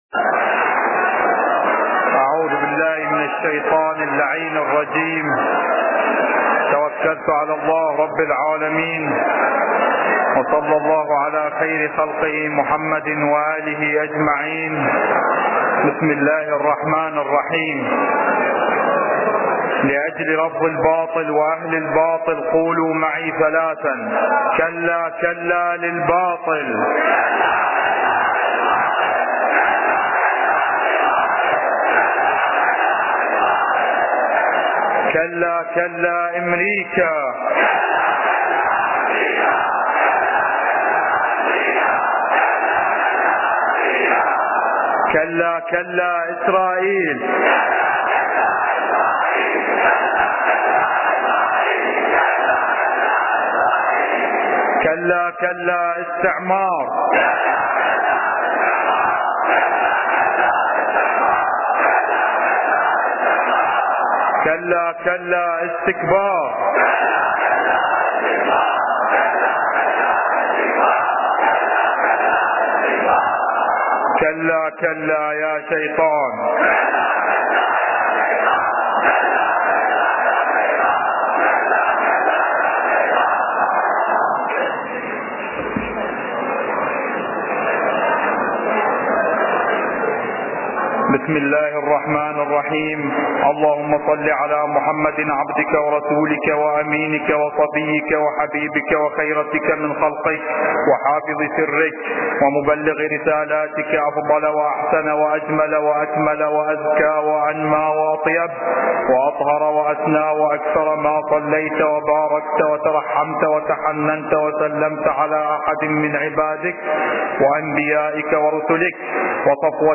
خطبتي صلاة الجمعة ( ٢١٥ ) للسيد القائد مقتدى الصدر
التسجيل الصوتي الكامل لخطبتي صلاة الجمعة ٢١٥ لسماحة حجة الاسلام والمسلمين السيد القائد مقتدى الصدر (أعزه الله ) التي اقيمت في مسجد الكوفة المعظم , للإستماع والتحميل